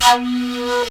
FLUTELIN05.wav